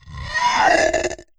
Monster_Death2.wav